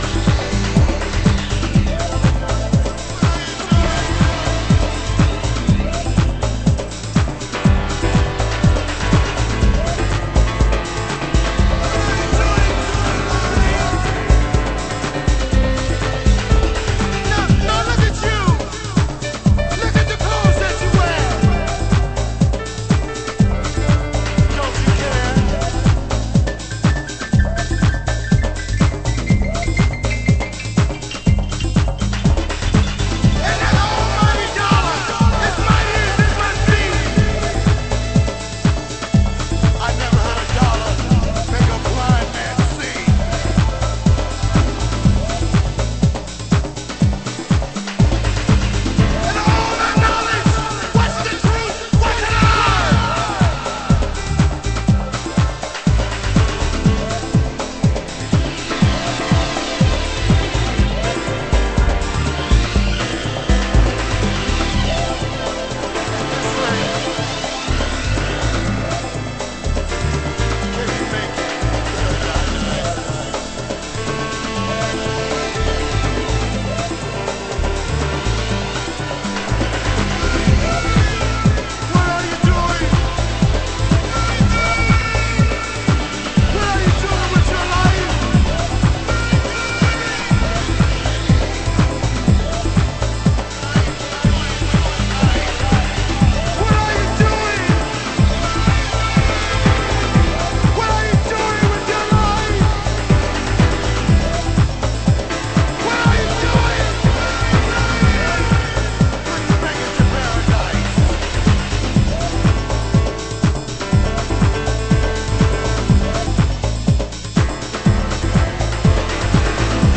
HOUSE MUSIC
(Vocal)
(Instrumental)　　　　盤質：軽いスレ傷による少しチリパチノイズ有